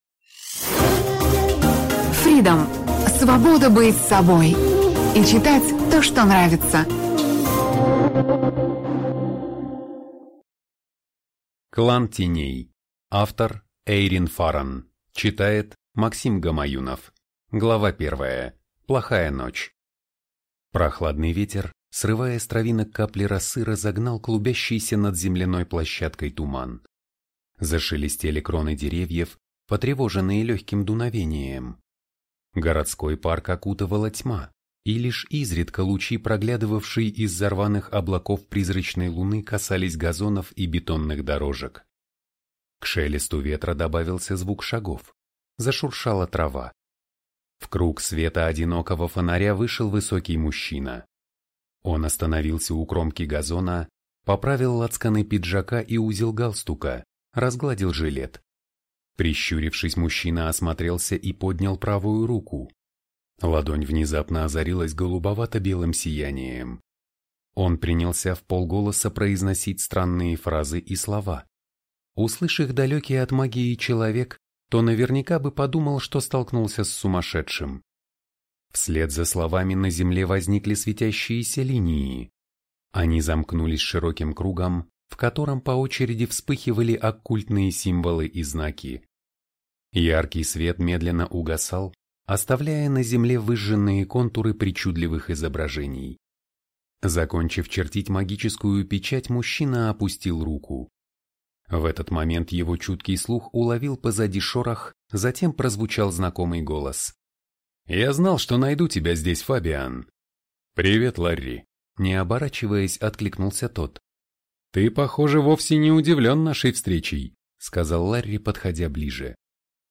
Аудиокнига Клан теней | Библиотека аудиокниг